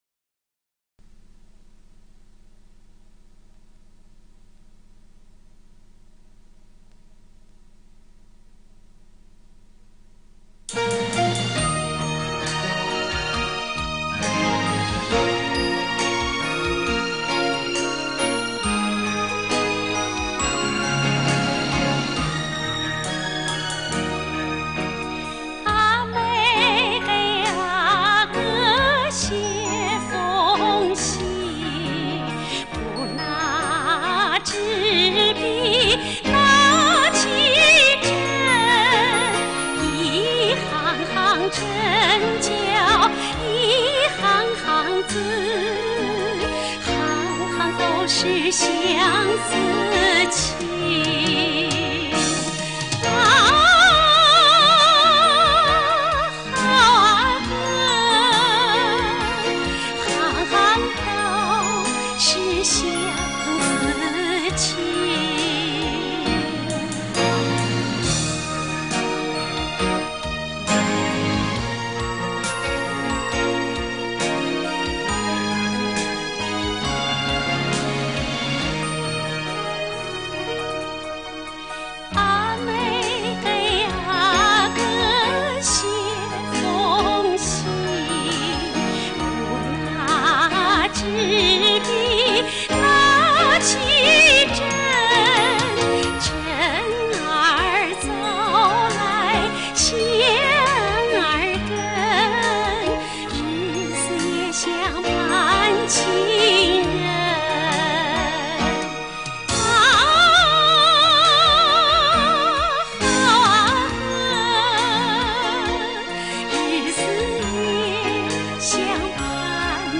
女高音歌唱家